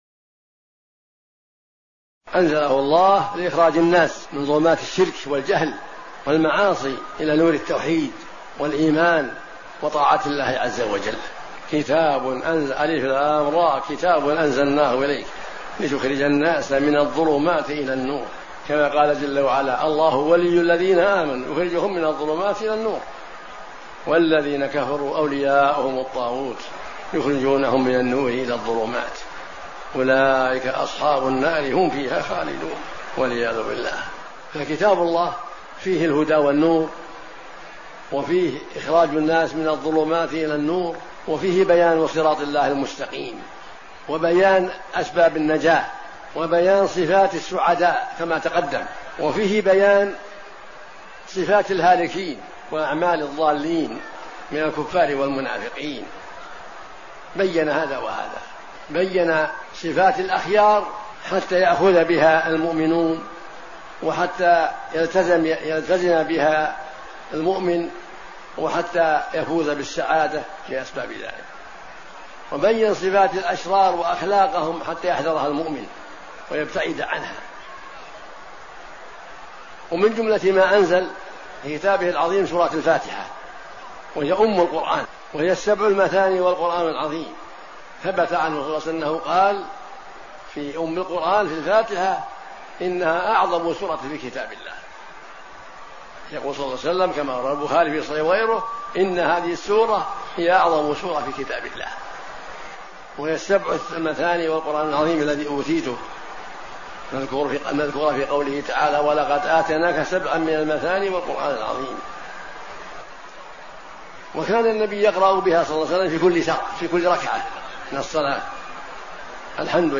شبكة المعرفة الإسلامية | الدروس | فضل سورة الفاتحة |عبدالعزيز بن عبداللة بن باز